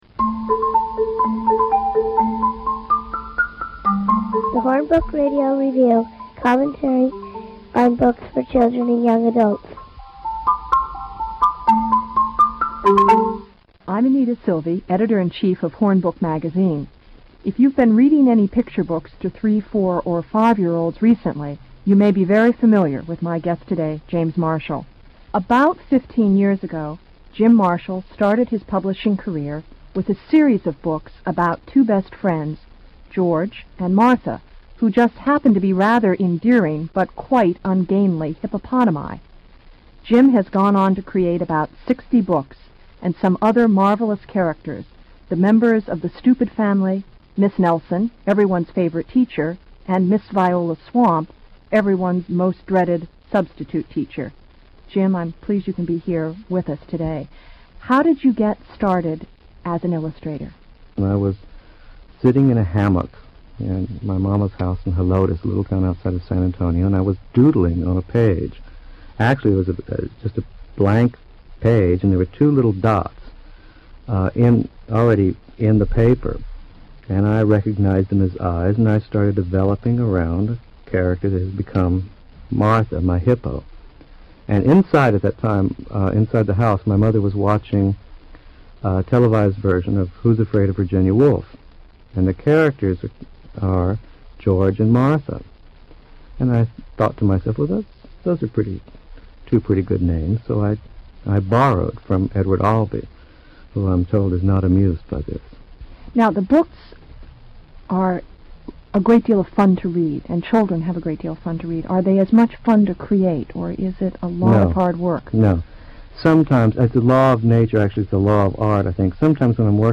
This program originally aired on National Public Radio in July or August of 1986.
Listen to the program.